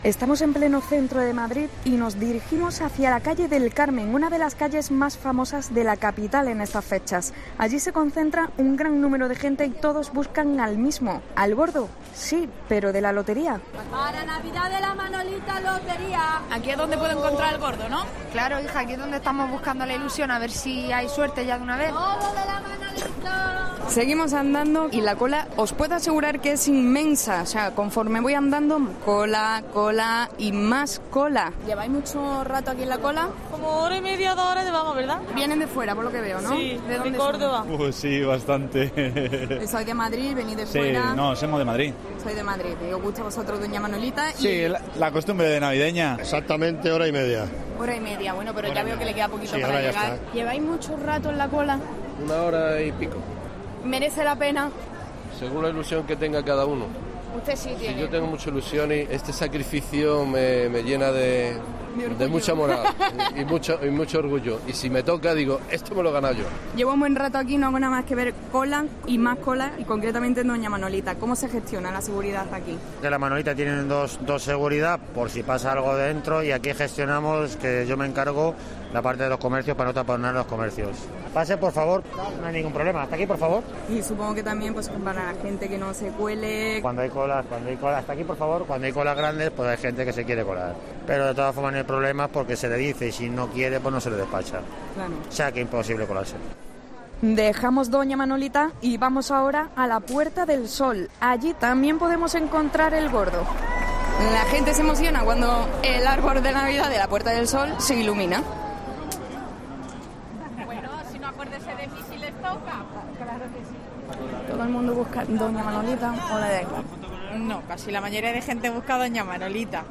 Hemos salido a la calle para tomarle el pulso a las calles de Madrid, para ello nos hemos dirigido hacía la Calle del Carmen, una de las calles más famosas de la Capital.